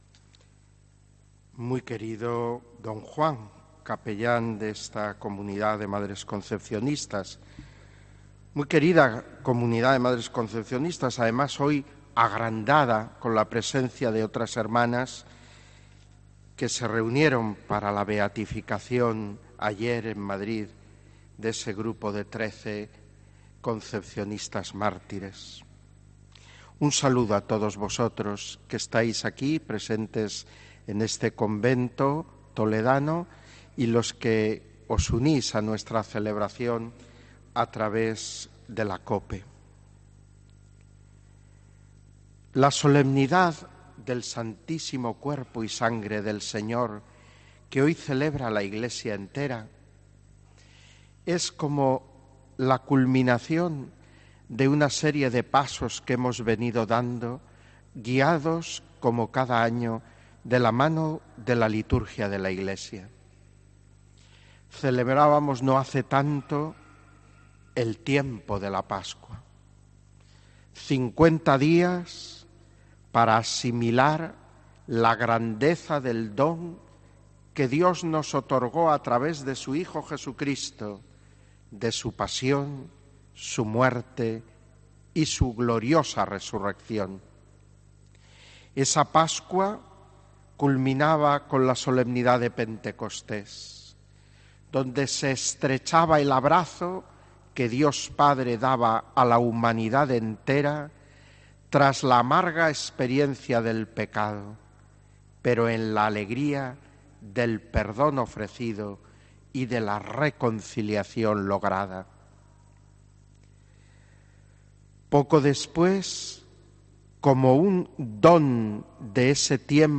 HOMILÍA 23 JUNIO 2019